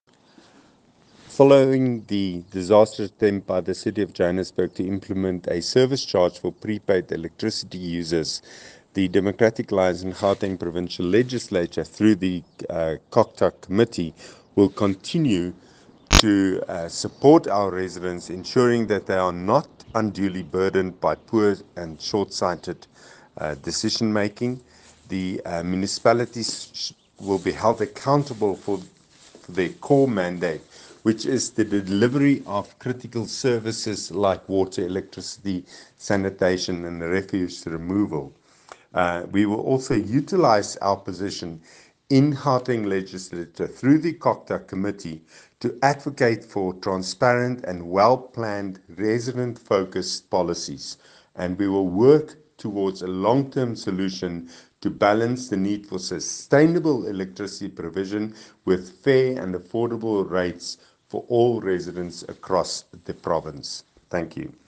Note to Editors:  Please find an English soundbite by Nico de Jager MPL